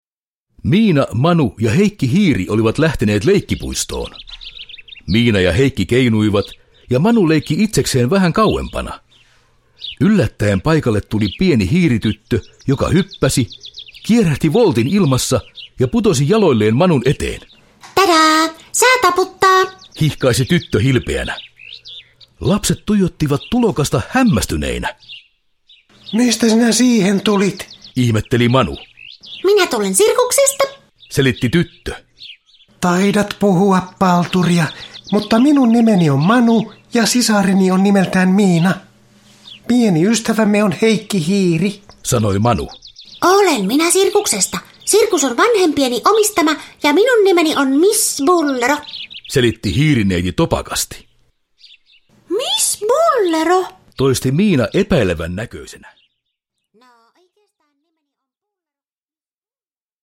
Miina ja Manu sirkuksessa – Ljudbok – Laddas ner